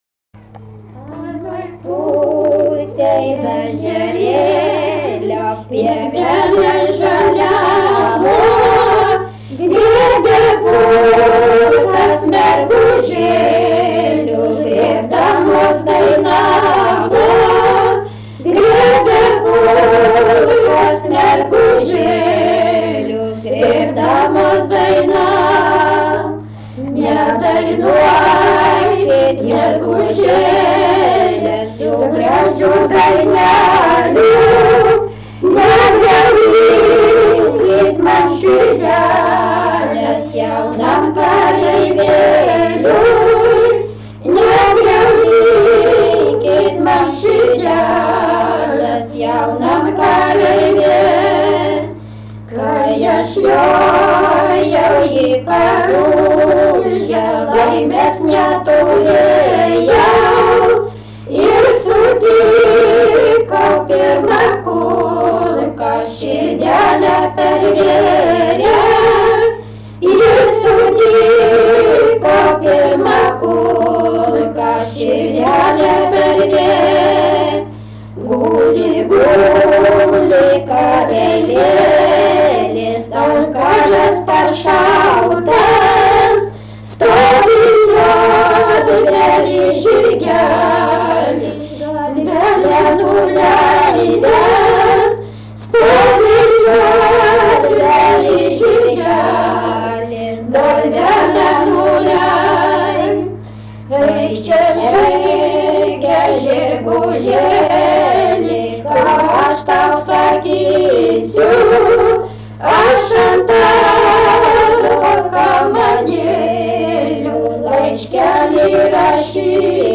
daina
Šepeta
vokalinis